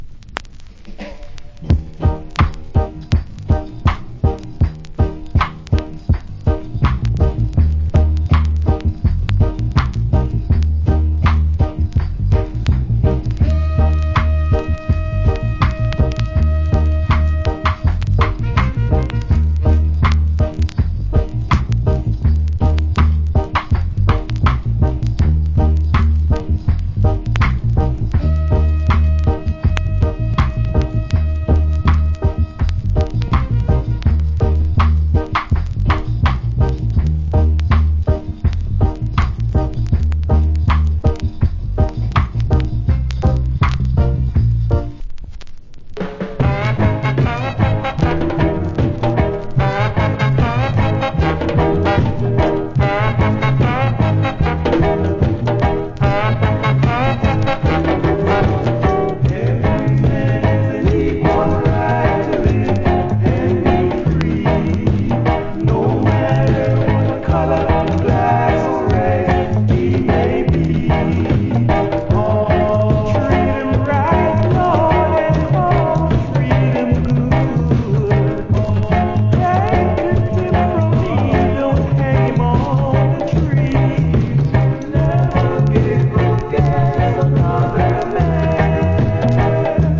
Wicked Reggae Inst.